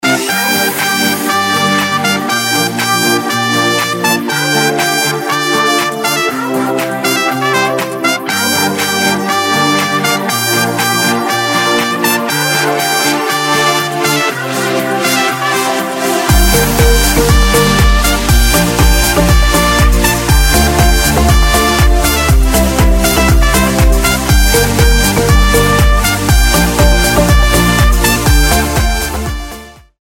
• Качество: 256, Stereo
красивые
dance
без слов
труба
Trumpets
Красивая игра на трубе под инструментальную музыку